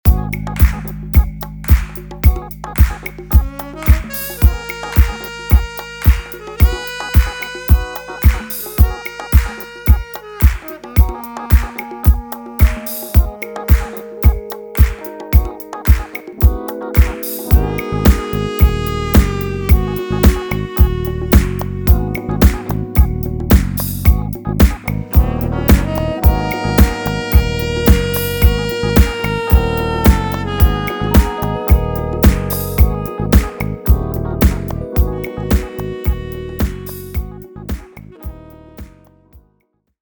Género: Electrónica.